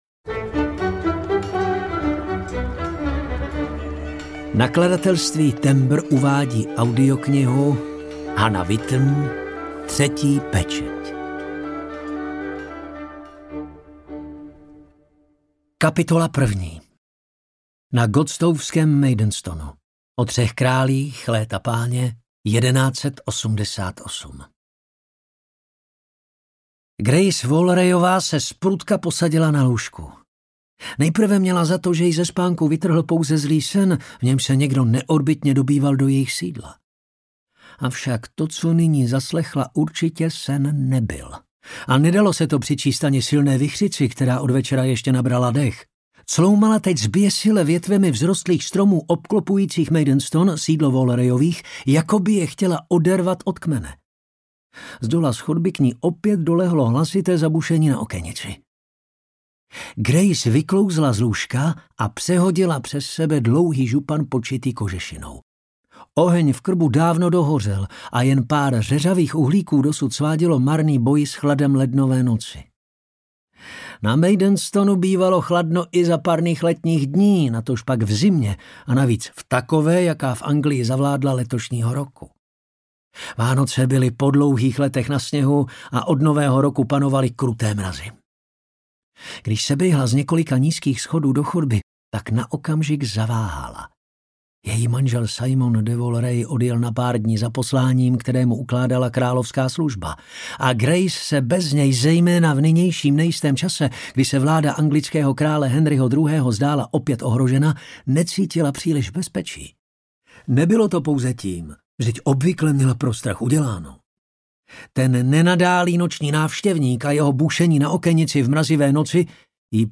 Třetí pečeť audiokniha
Ukázka z knihy